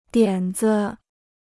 点子 (diǎn zi) Kostenloses Chinesisch-Wörterbuch